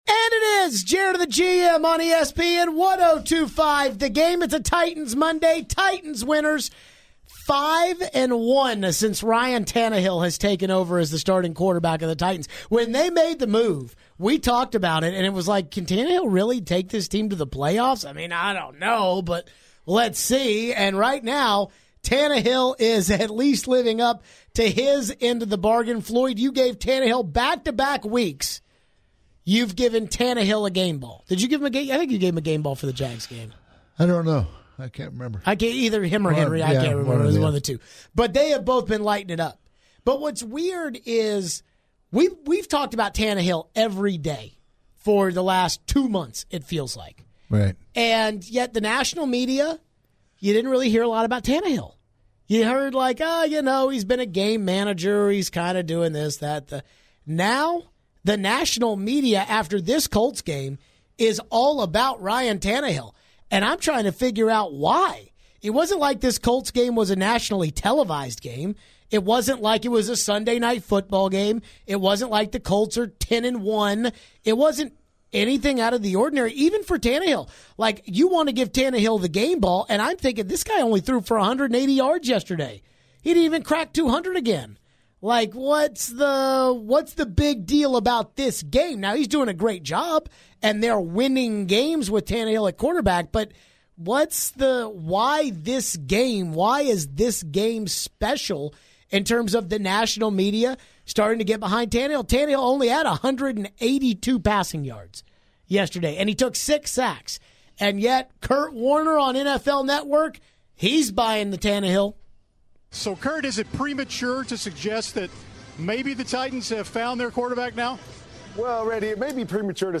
(0:48) - Callers on Tannehill (12:12) - Is Tannehill a threat to throw the deep ball?